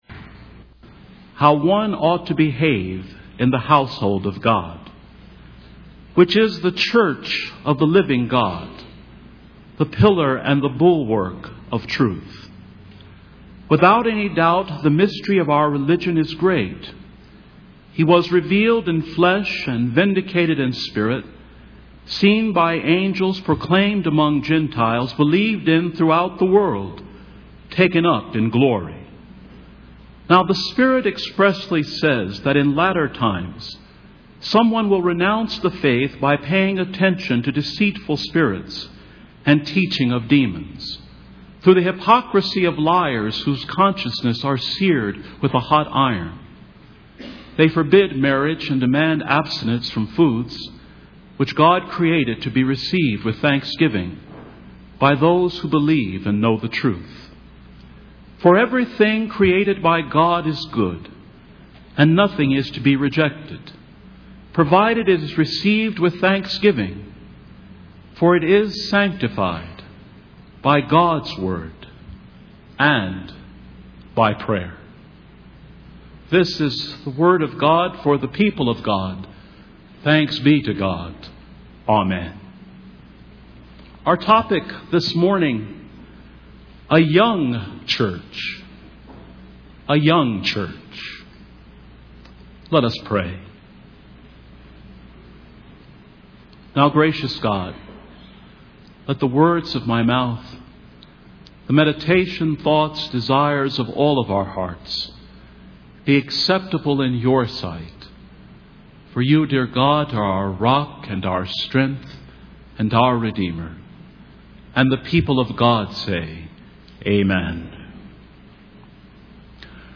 2005 3.1 meg mp3 file Download Now There was an apparent error when the sermon was recorded this morning.
The error was noticed, for that's when the recording started. I apologize for the abrupt start of today's sermon.